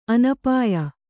♪ anapāya